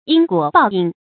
因果报应 yīn guǒ bào yìng 成语解释 因果：原因和结果。